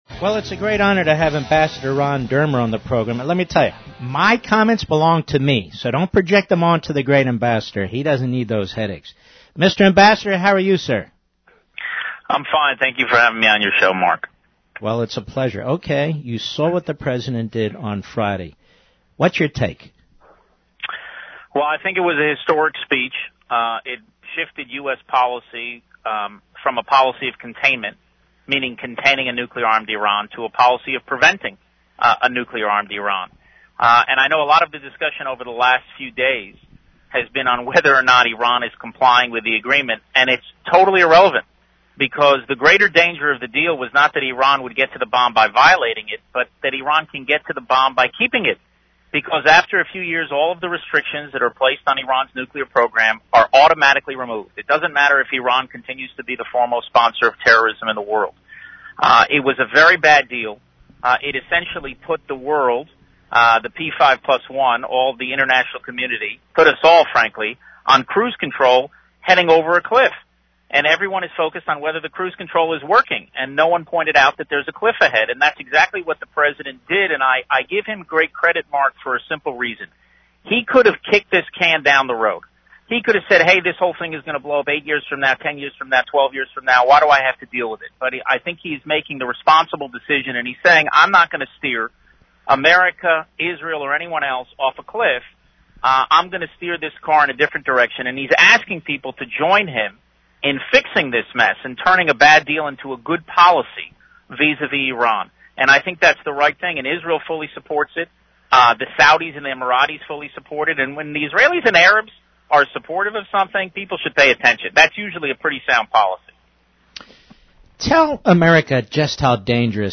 Israeli Ambassador Ron Dermer Joins Mark